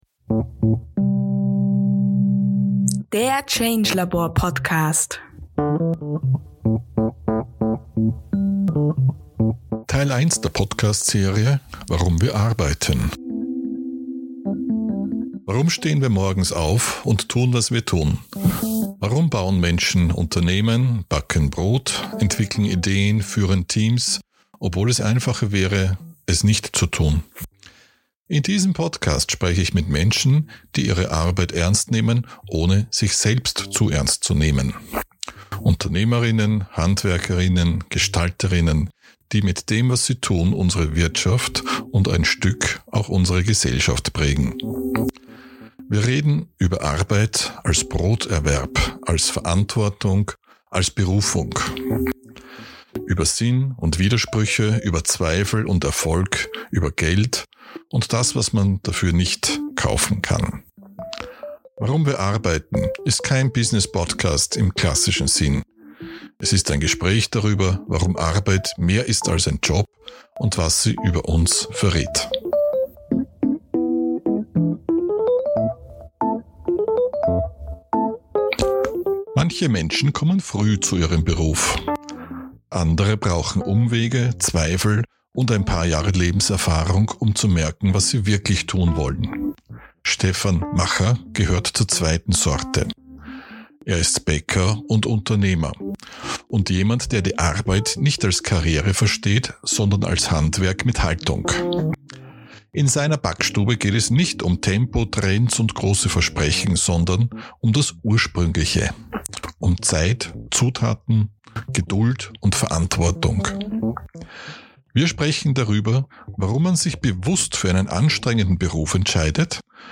Ein Interview